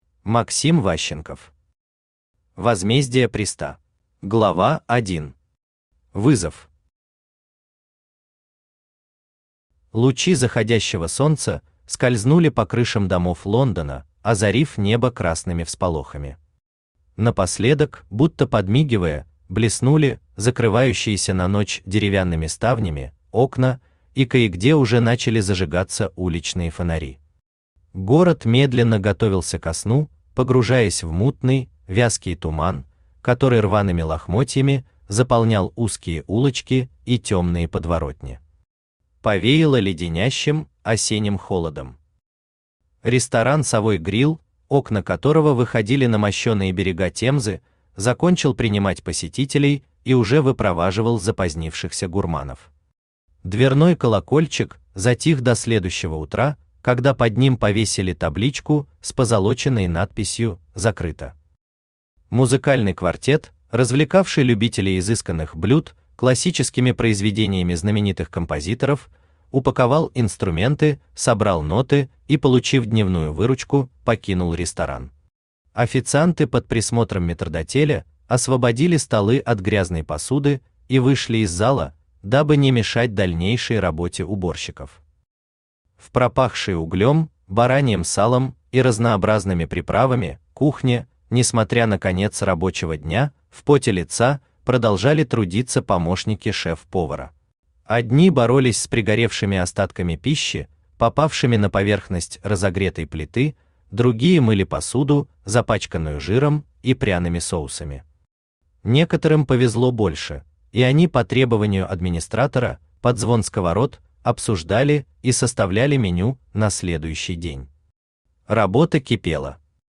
Аудиокнига Возмездие Приста | Библиотека аудиокниг
Aудиокнига Возмездие Приста Автор Максим Ващенков Читает аудиокнигу Авточтец ЛитРес.